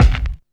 CRUNCH.wav